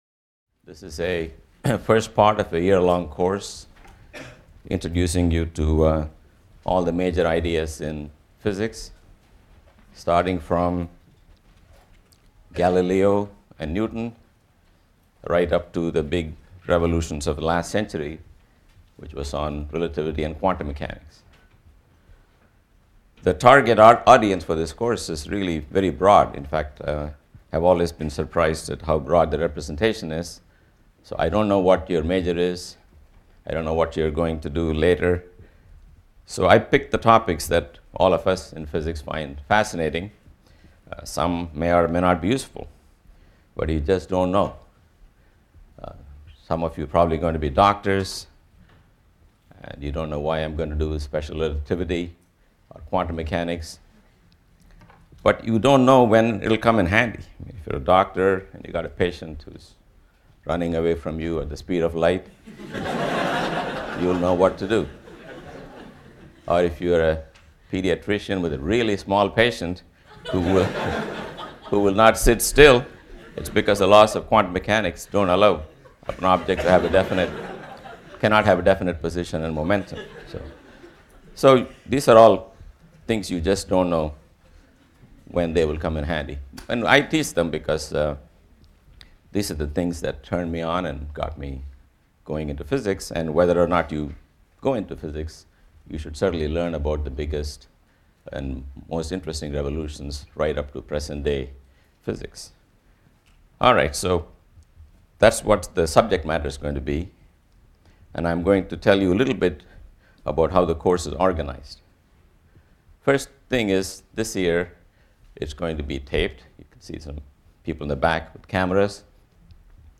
PHYS 200 - Lecture 1 - Course Introduction and Newtonian Mechanics | Open Yale Courses